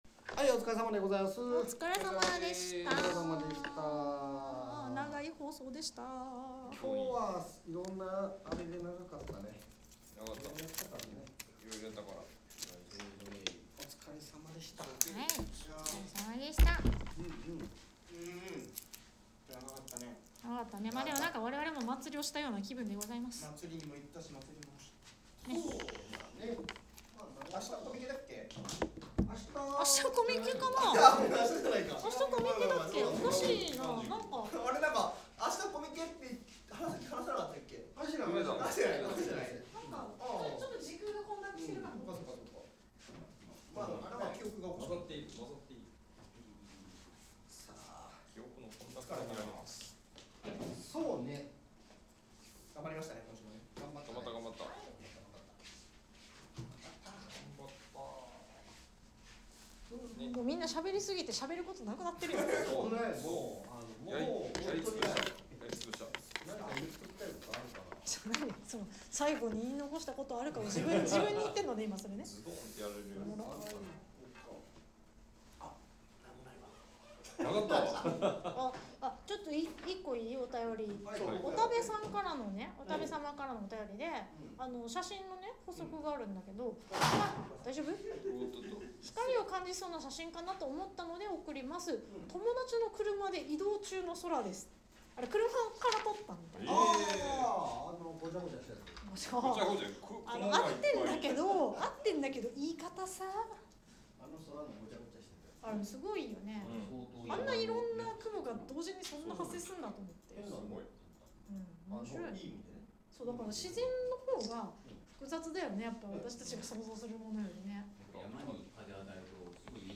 ■楽屋裏トーク■ https